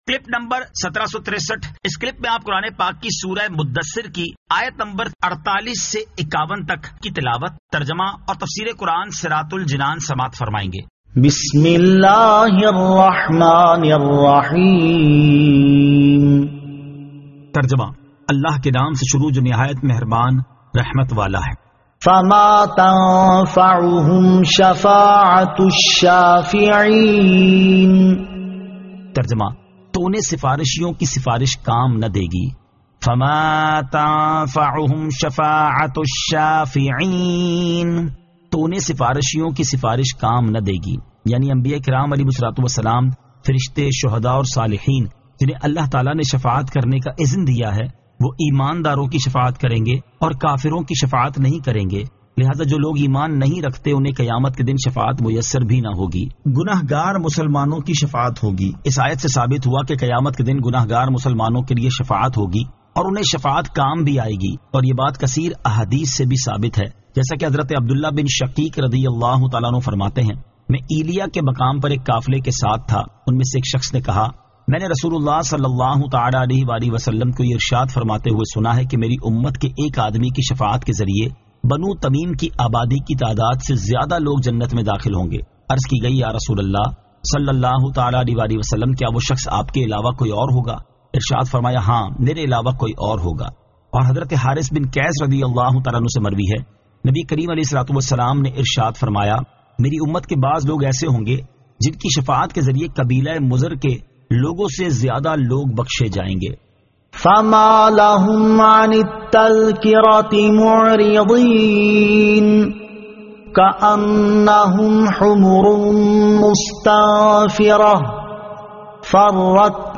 Surah Al-Muddaththir 48 To 51 Tilawat , Tarjama , Tafseer